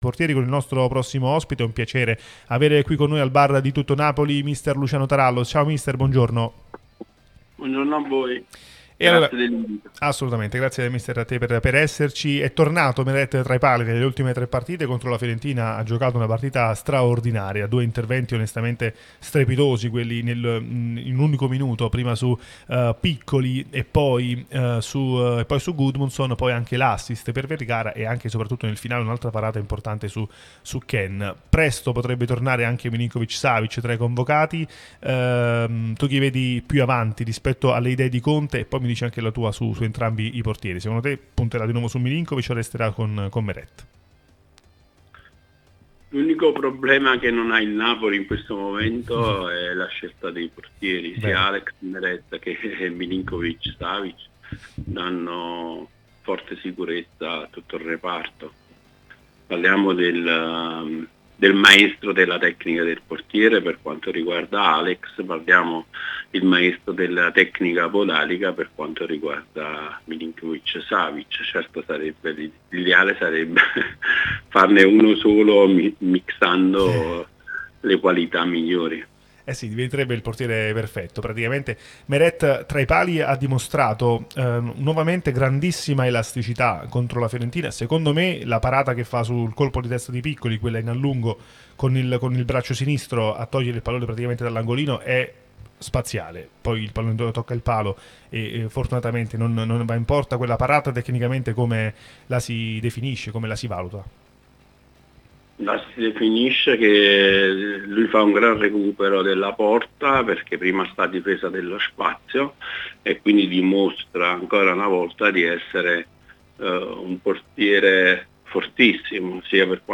Podcast Ex prep. portieri: "Meret?